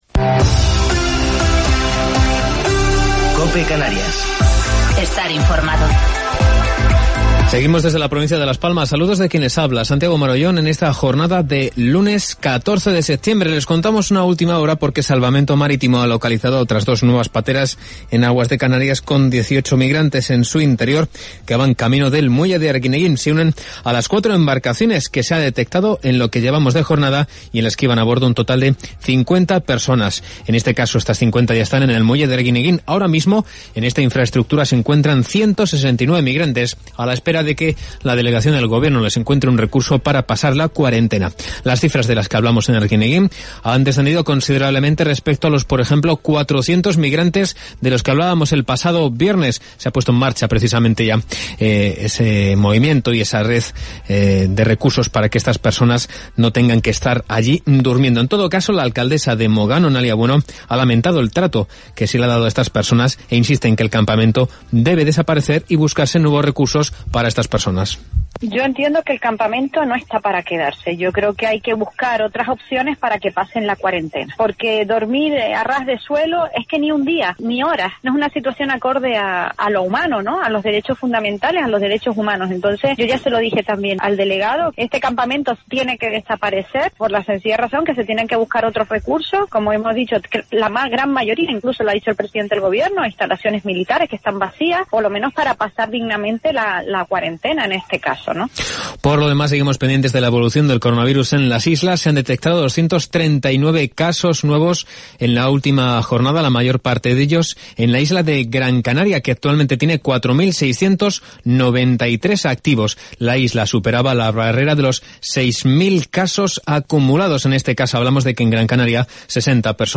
Informativo local 14 de Septiembre del 2020